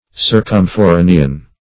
Circumforanean \Cir`cum*fo*ra"ne*an\